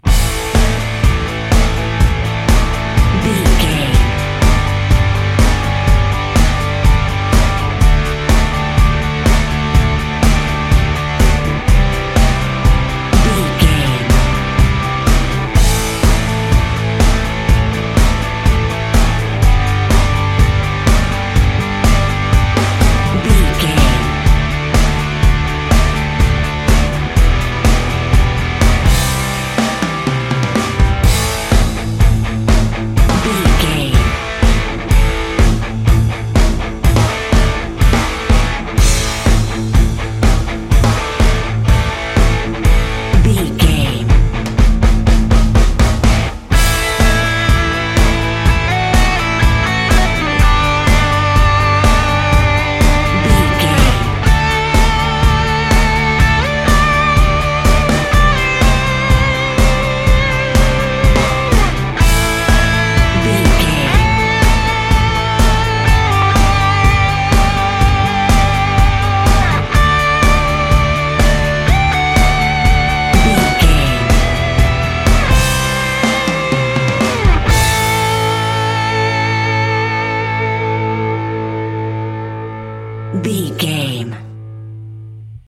Ionian/Major
drums
electric guitar
Sports Rock
hard rock
bass
aggressive
energetic
intense
nu metal
alternative metal